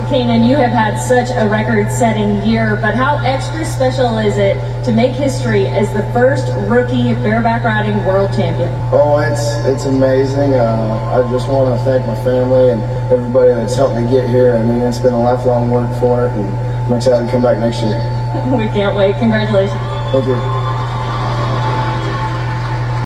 It happened Sunday night in Las Vegas at the National Finals Rodeo.